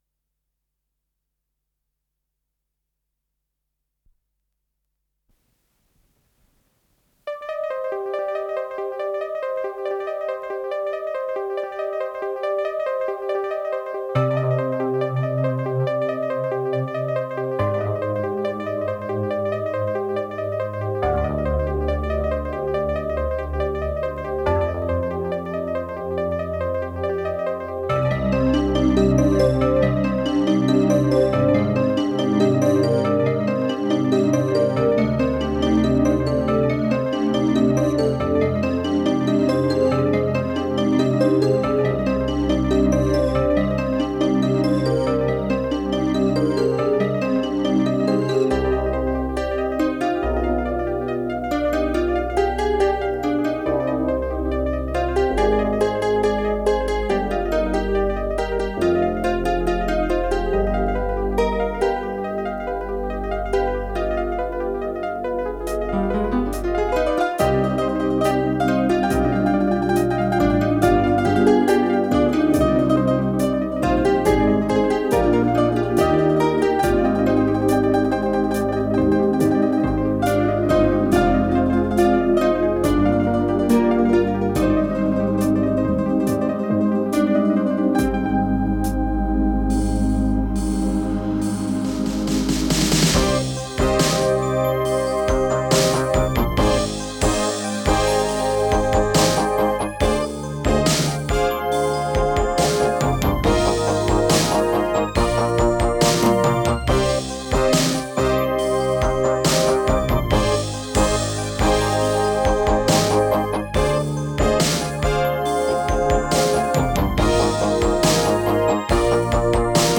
с профессиональной магнитной ленты
Скорость ленты38 см/с
ВариантДубль моно